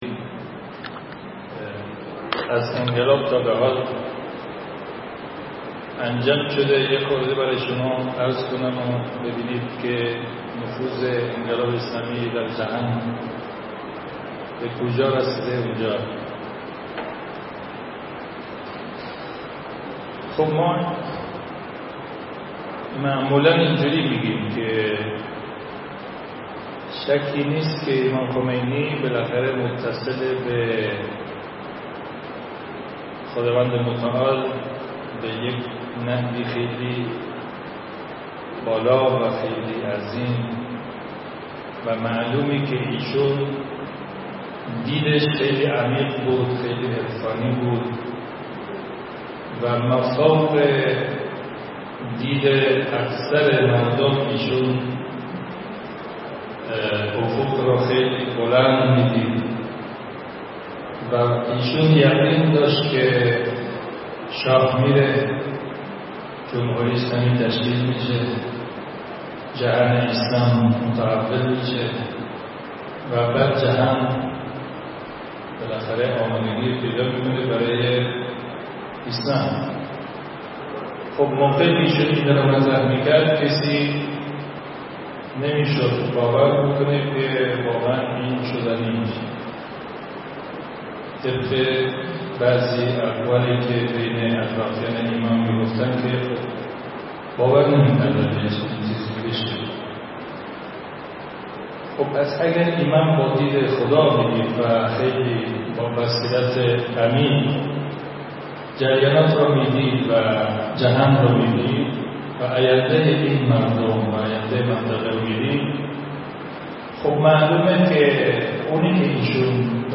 نشست علمی